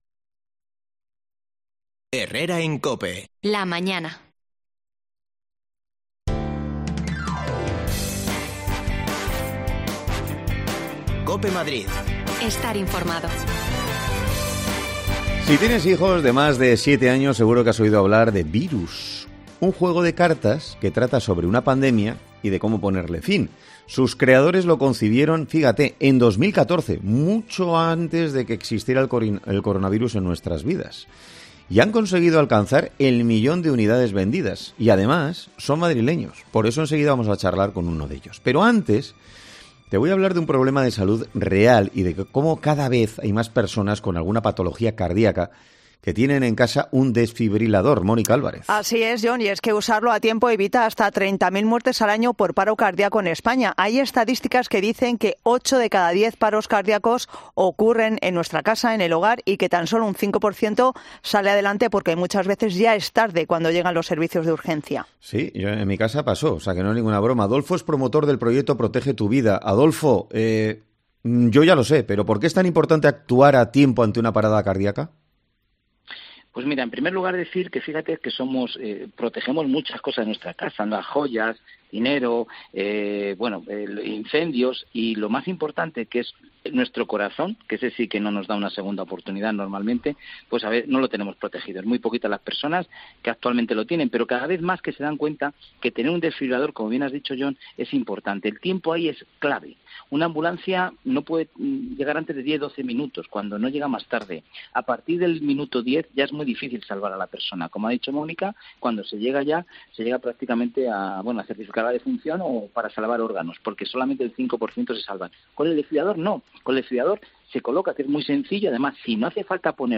Hablamos con ellos para que nos cuenten cómo ha empezado todo
Las desconexiones locales de Madrid son espacios de 10 minutos de duración que se emiten en COPE , de lunes a viernes.